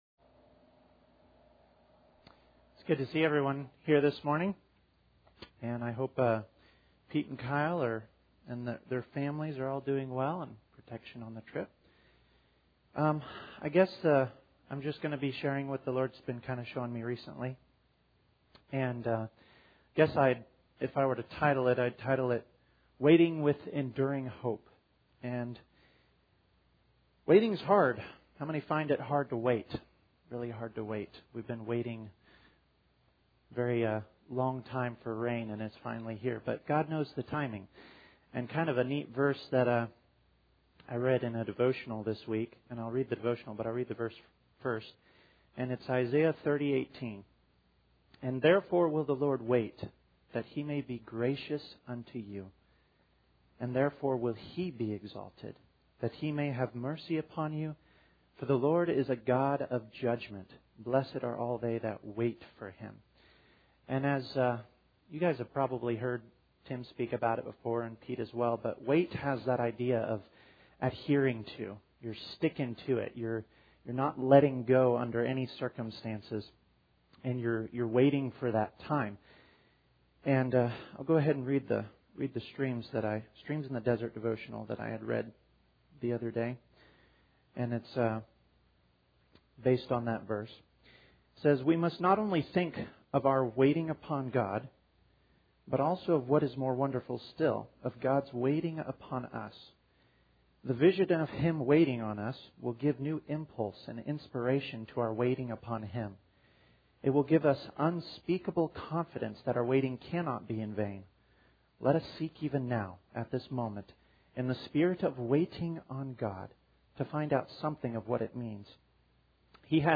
; Psalm 62:5-8; Proverbs 10:28; Proverbs 23:17-18; Psalm 37:25; Philippians 4:4-7; Colossians 3:15 Service Type: Sunday Morning Audio Version Below (Not intended to play with video.)